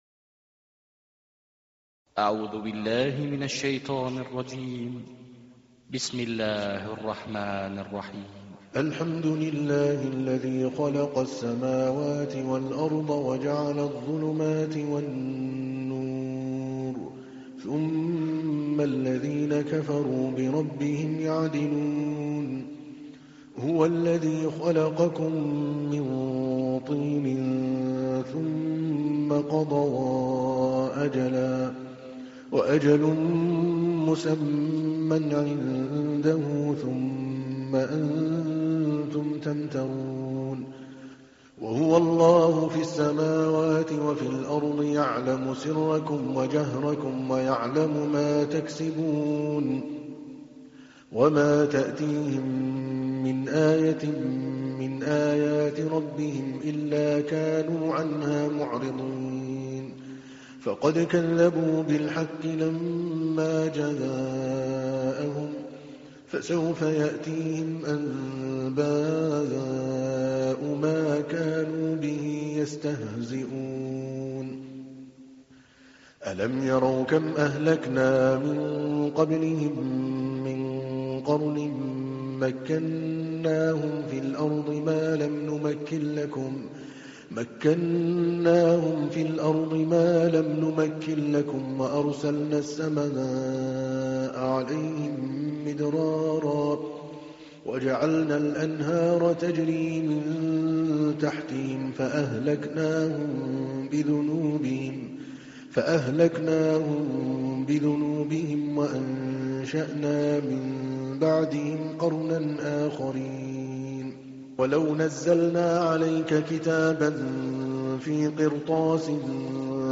تحميل : 6. سورة الأنعام / القارئ عادل الكلباني / القرآن الكريم / موقع يا حسين